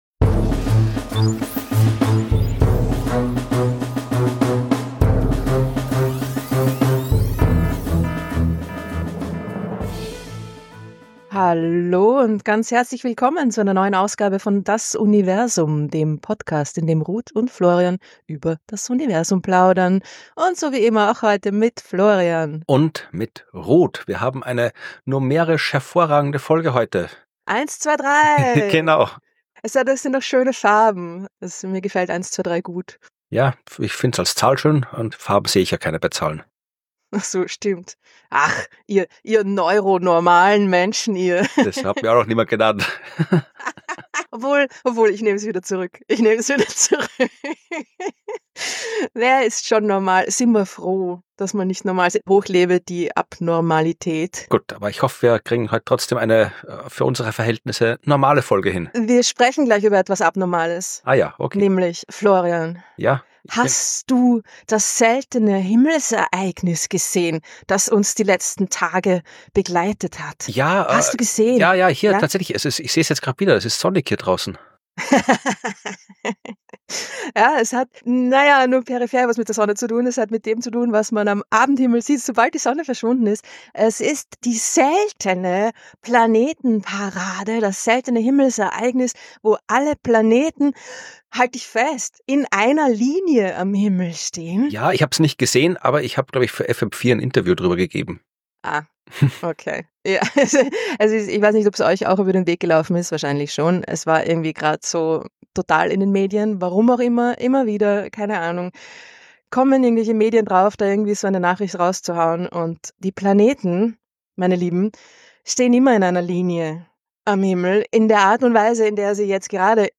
In jeder Folge erzählen sie einander eine spannende Geschichte aus der aktuellen Forschung. Und beantworten Fragen aus der Hörerschaft zu allem was man gerne über das Universum wissen möchte.